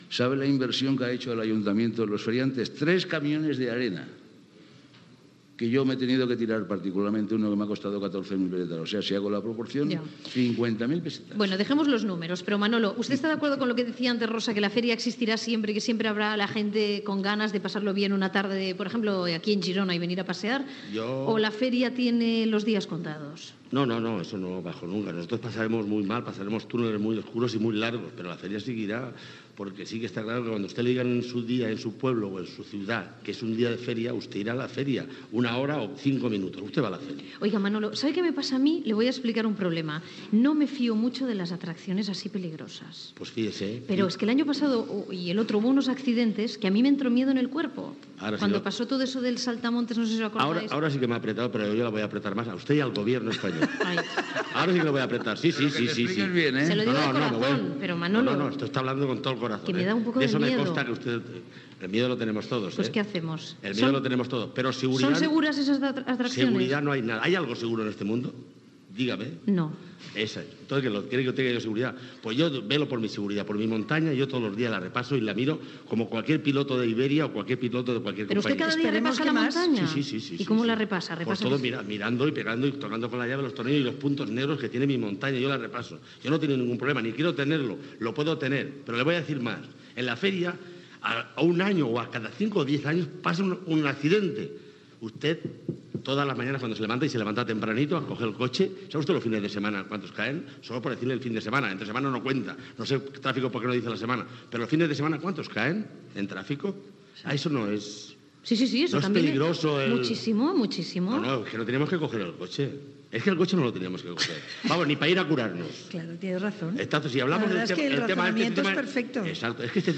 0c73084eedf23fbf658d7cb5c8a2b47ba9492f3b.mp3 Títol Cadena SER Emissora Ràdio Girona Cadena SER Titularitat Privada estatal Nom programa La ventana Descripció Programa fet des del Palau Firal de Girona amb motiu de les fires i festes de Sant Narcís. Conversa sobre la seguretat de les atraccions de la fira.